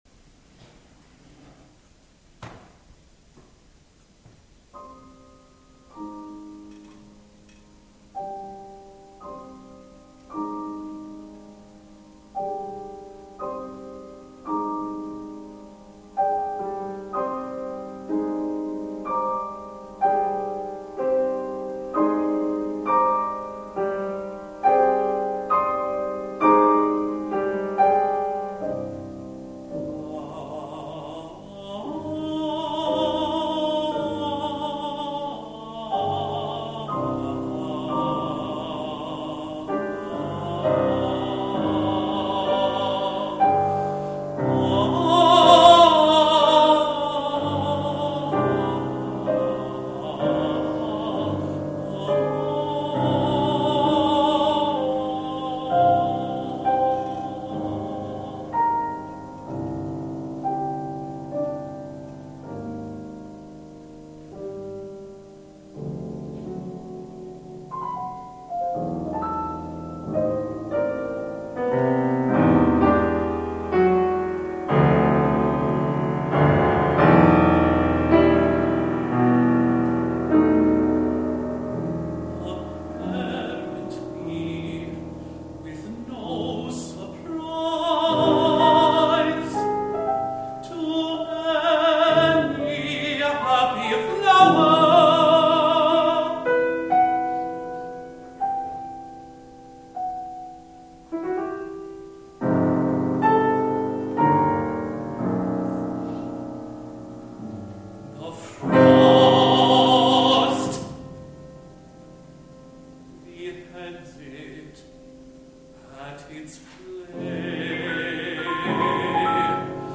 Apparently With No Surprise (voice and piano) – 2010 | Roman Designs
at Arizona State University